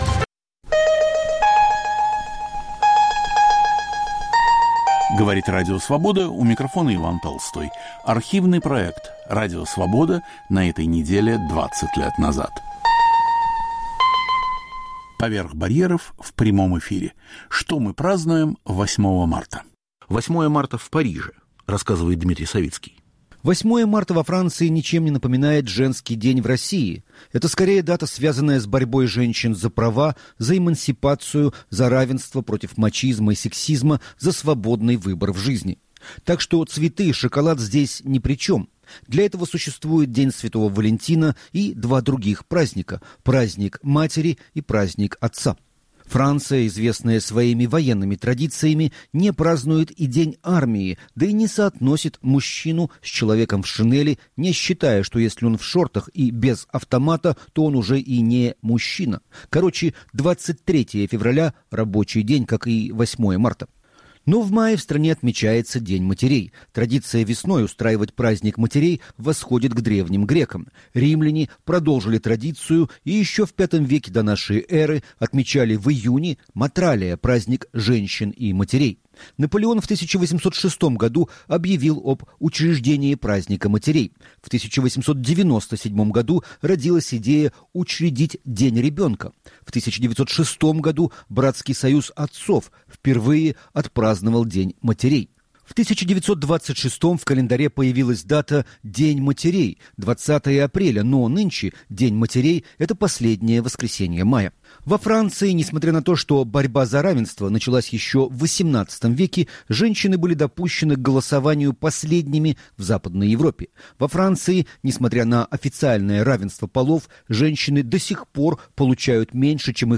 "Поверх барьеров" в прямом эфире. Что мы празднуем 8 марта